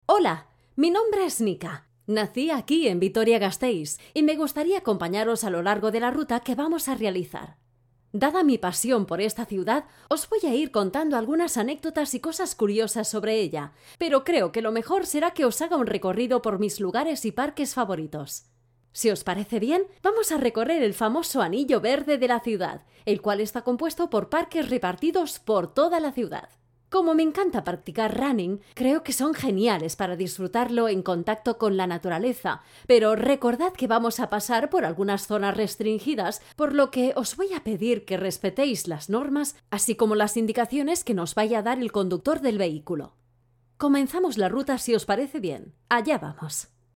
kastilisch
Sprechprobe: eLearning (Muttersprache):
I do not notice any accent.